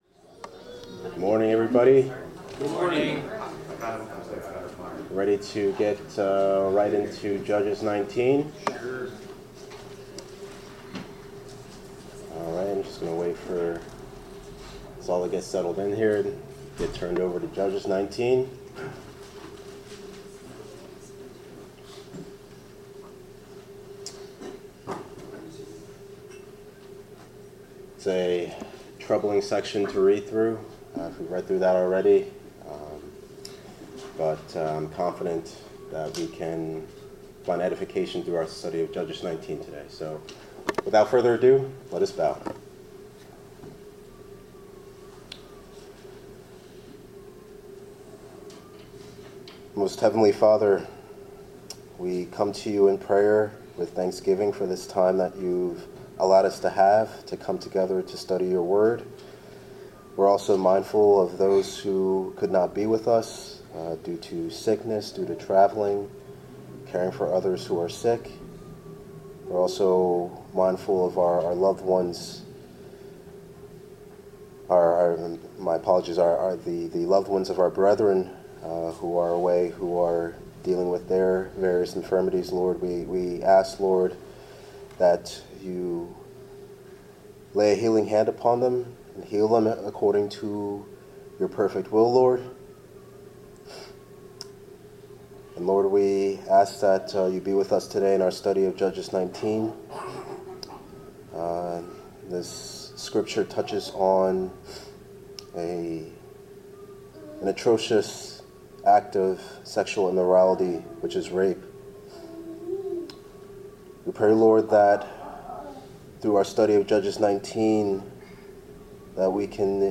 Bible class: Judges 19
Service Type: Bible Class